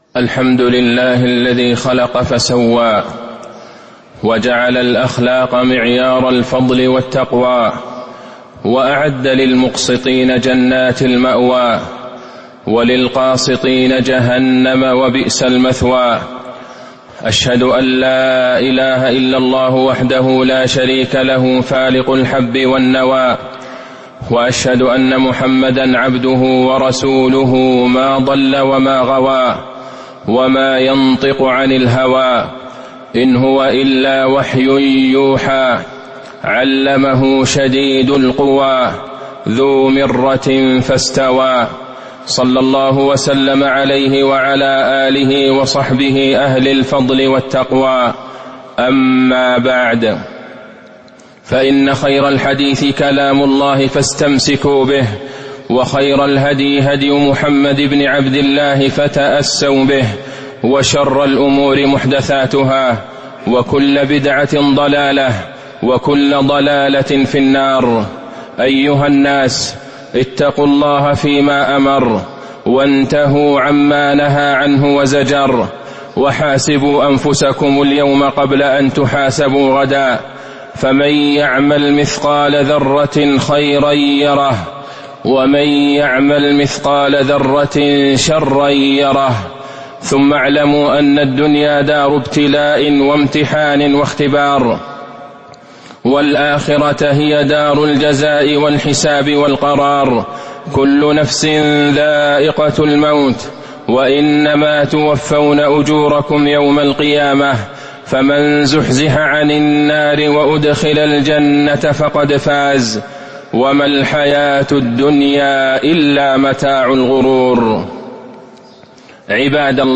تاريخ النشر ٢٠ جمادى الآخرة ١٤٤٤ هـ المكان: المسجد النبوي الشيخ: فضيلة الشيخ د. عبدالله بن عبدالرحمن البعيجان فضيلة الشيخ د. عبدالله بن عبدالرحمن البعيجان الله الله في حقوق الناس